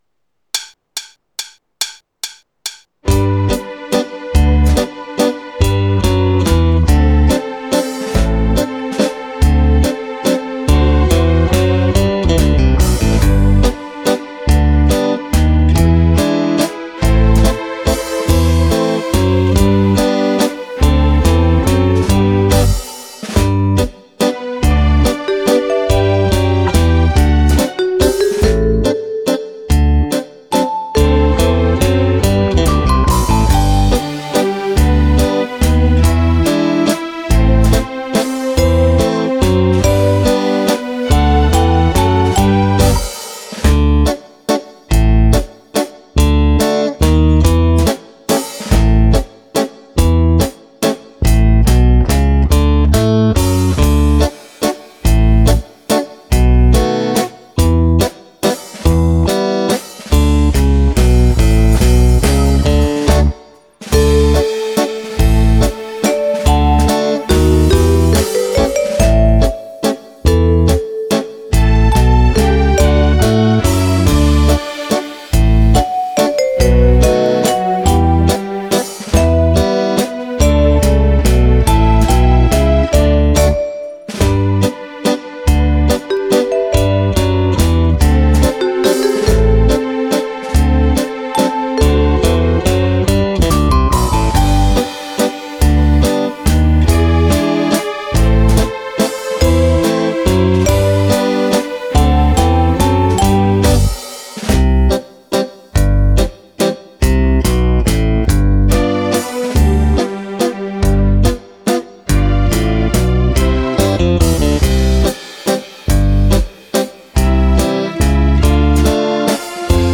2 brani per Fisarmonica
Mazurka